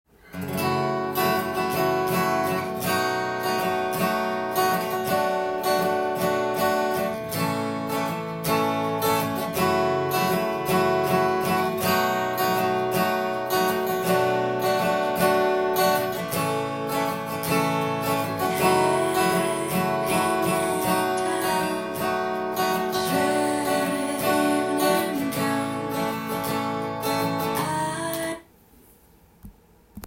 音源に合わせて譜面通り弾いてみました
歌の方は、癒し系で非常にシンプルで心に染みます。
Em7、A7、Am7，G